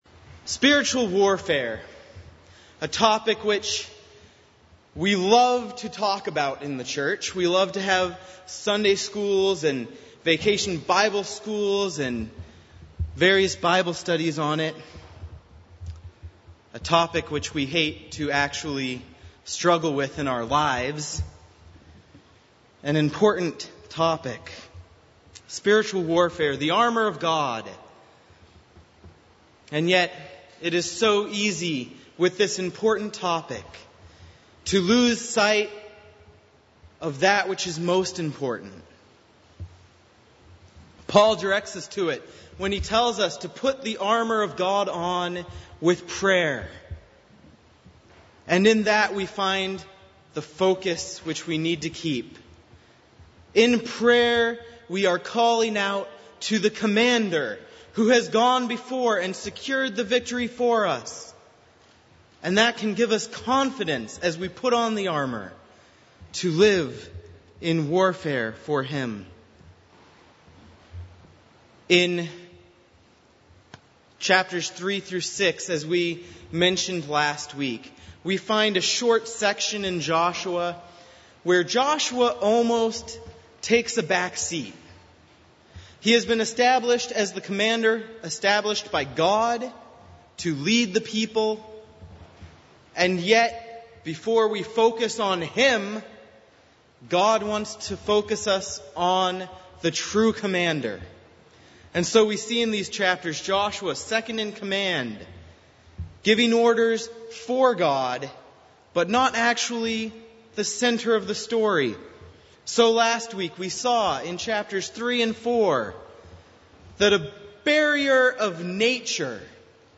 Passage: Joshua 6:1-27, Hebrews 11:22-12:2 Service Type: Sunday Evening